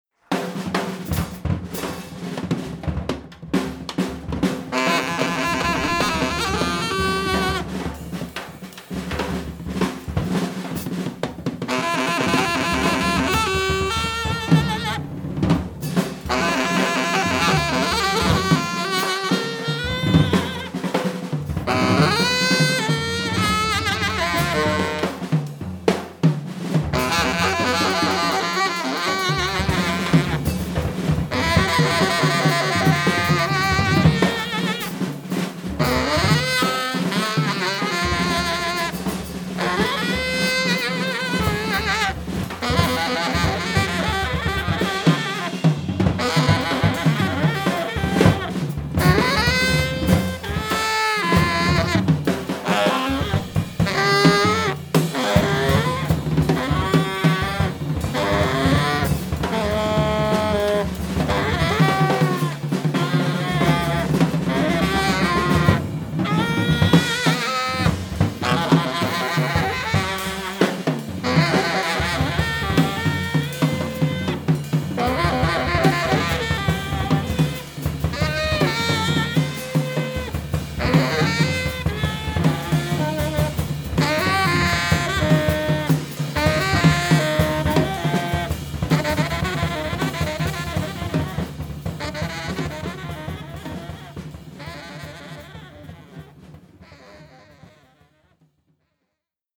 FreeJazz - Sample
September 2007, Thomasberg
Basic Mix